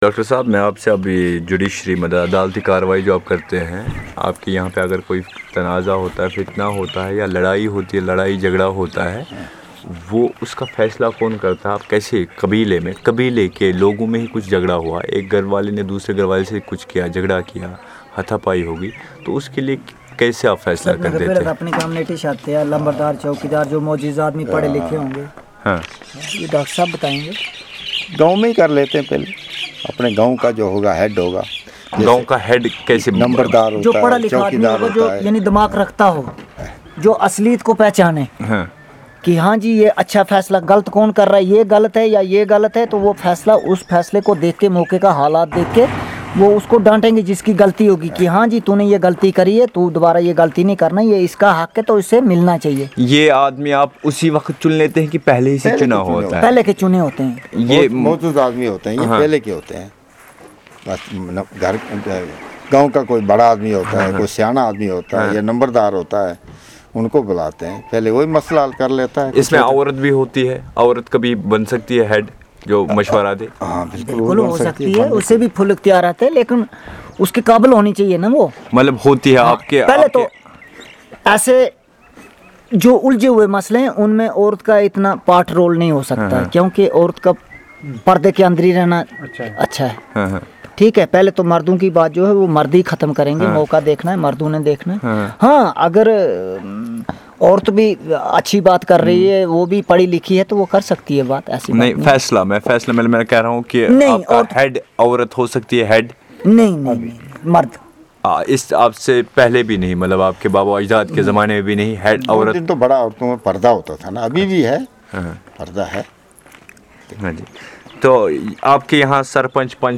Conversation on the judicial system of the community
NotesThis is a conversation on the judicial system of the community and how they settle disputes.